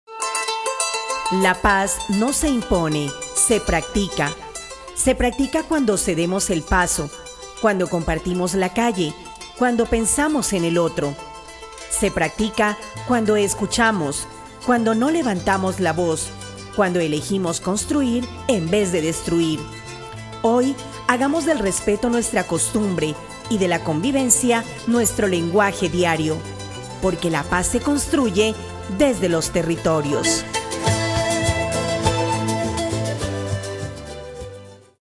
PERIFONEOS
Esta serie de audios recoge el perifoneo callejero como estrategia de comunicación territorial y comunitaria. Desde las calles, la voz amplificada informa, convoca y fortalece los vínculos sociales en el territorio.